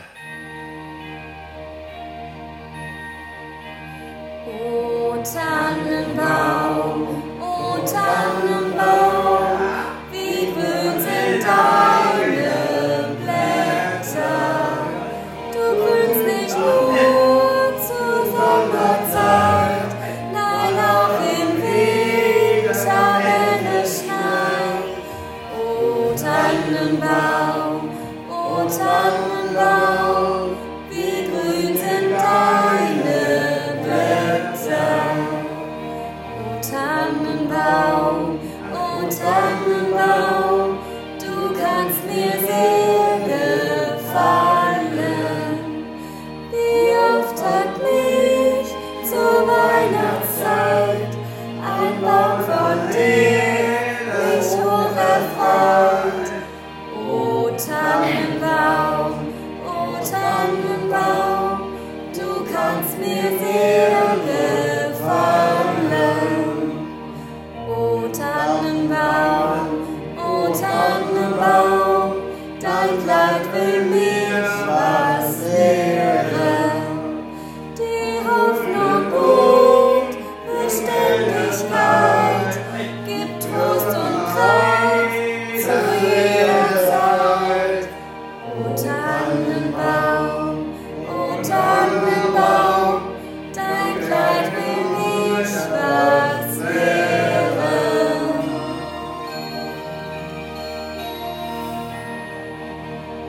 Wir haben das Lied „O Tannenbaum“ in unserer Tagesstruktur eingesungen.